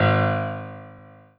piano-ff-12.wav